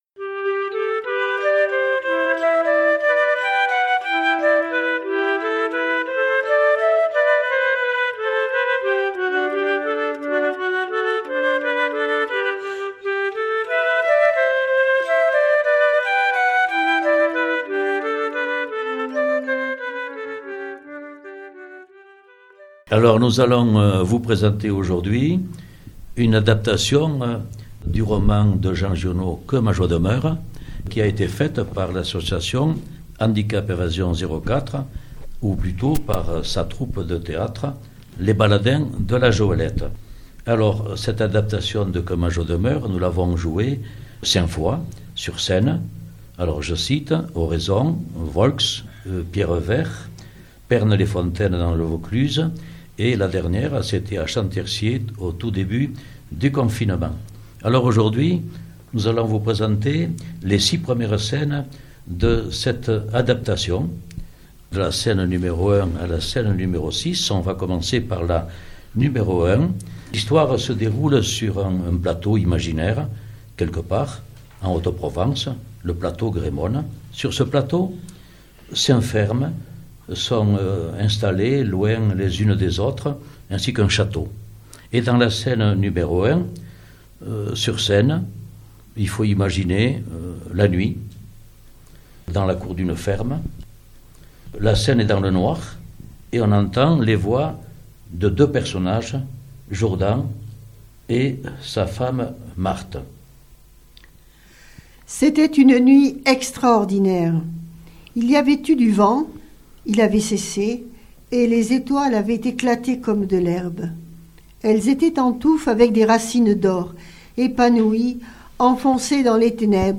La troupe les "Baladins de la Joëlette" joue Giono !